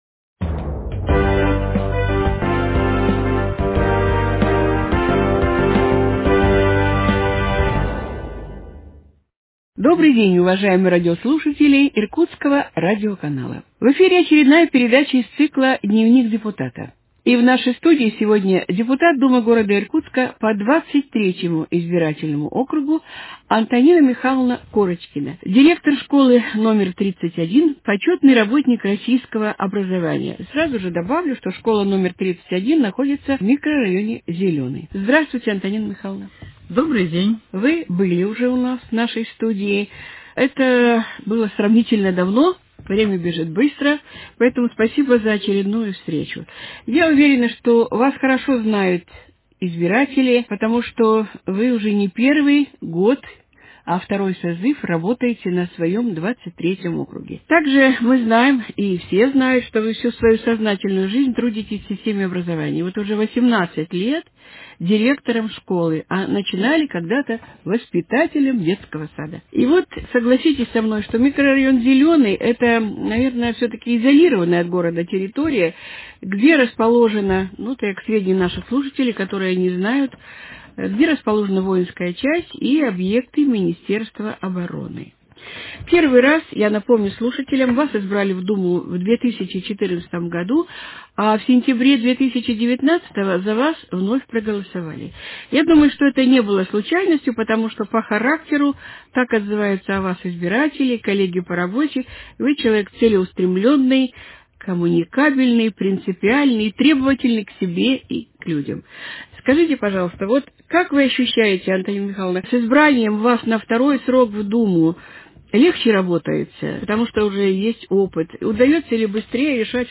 Депутат Городской Думы по избирательному округу № 23 Антонина Михайловна Корочкина, директор школы № 31 г. Иркутска рассказывает о трудностях и успехах в депутатской деятельности, об особенностях и проблемах работы школ в период пандемии.